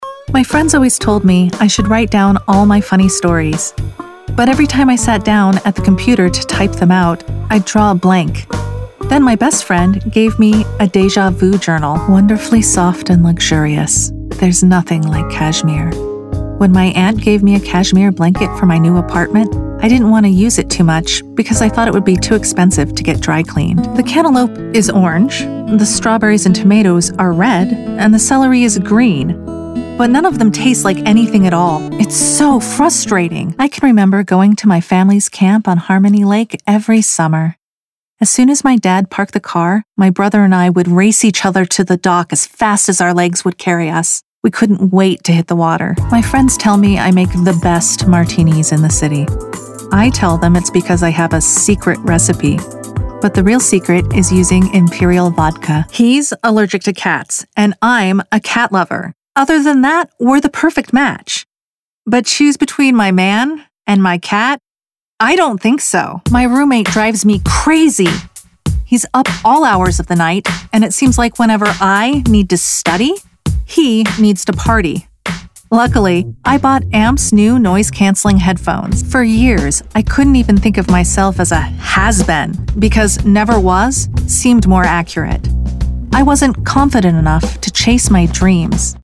Commercial demo
Young Adult
Middle Aged